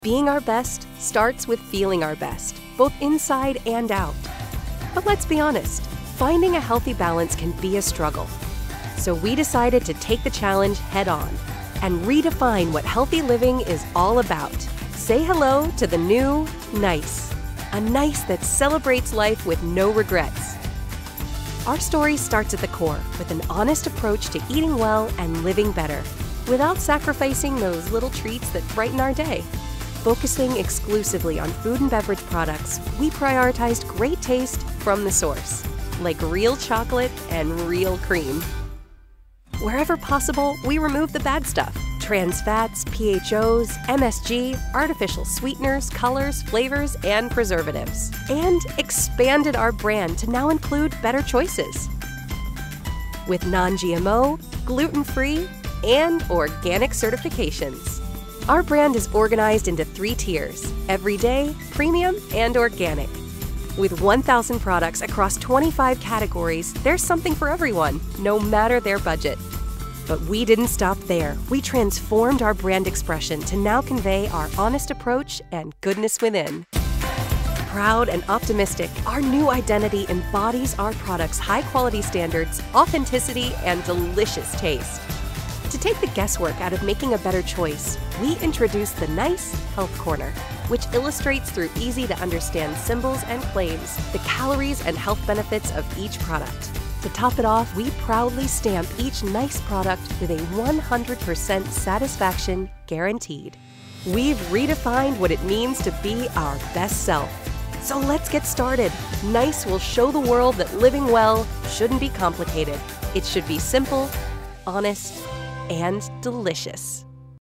Female Voice Over, Dan Wachs Talent Agency.
Sassy, Dramatic, Conversational.
Corporate